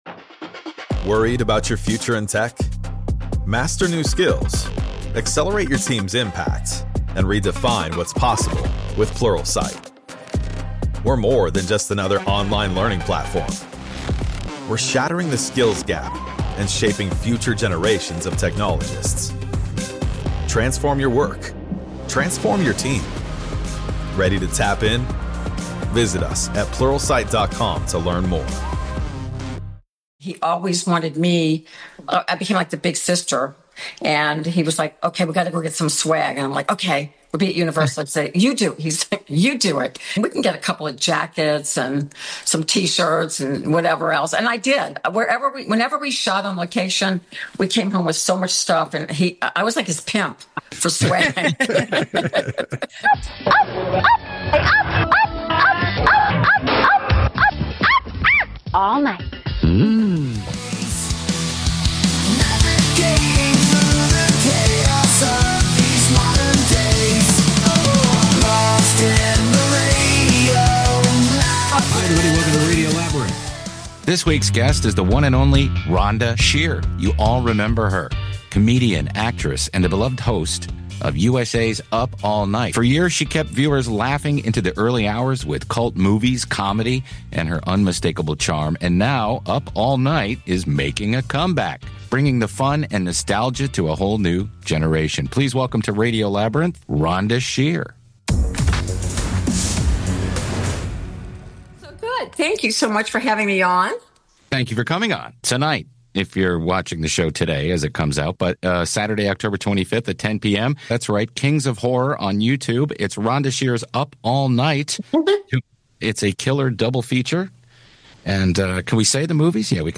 UP ALL NIGHT RETURNS! | Rhonda Shear Interview